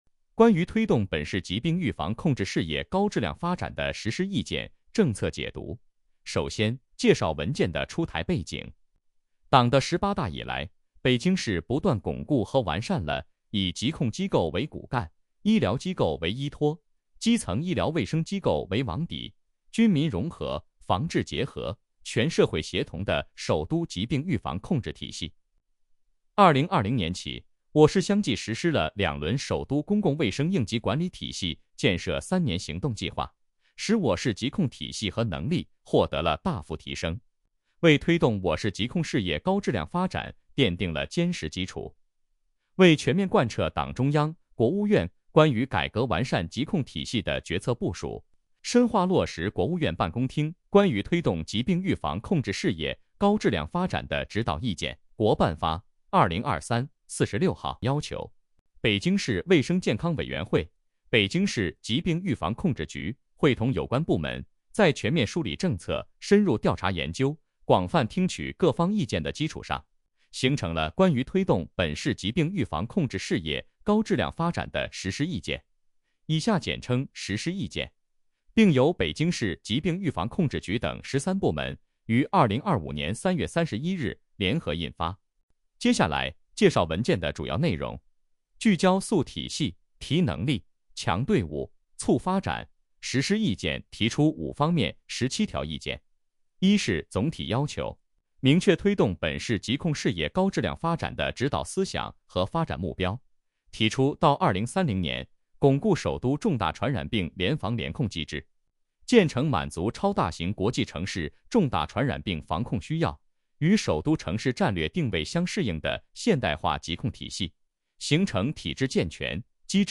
音频解读：北京市疾病预防控制局等13部门关于推动本市疾病预防控制事业高质量发展的实施意见